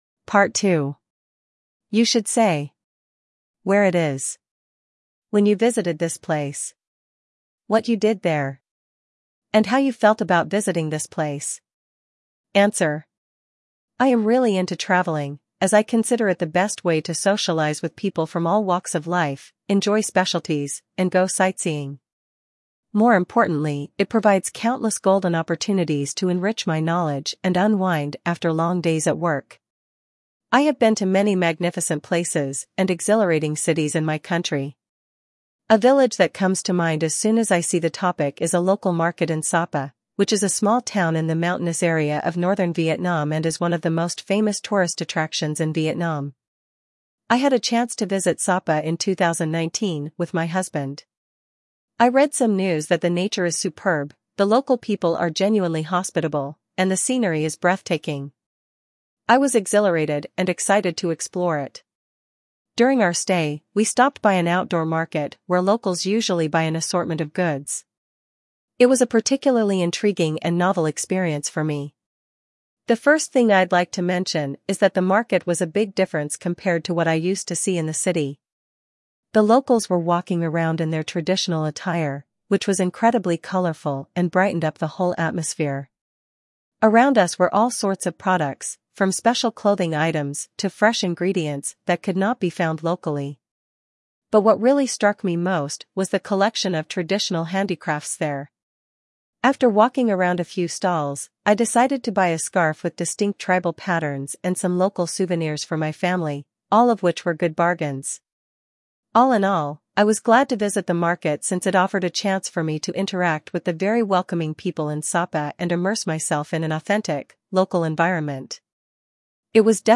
Jenny (English US)